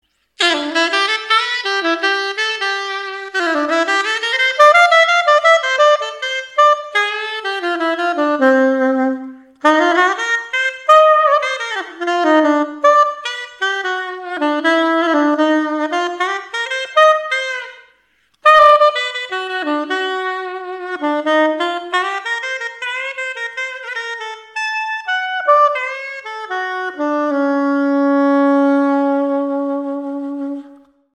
NEW     Just testing out the (very cheap) Acoustica Mixcraft software,
another doodle with the Conn C-Sop and Meyer (Bb) mouthpiece, using a plain old Rico V2 cane reed.  Slight touch of compression, reverb and chorus.....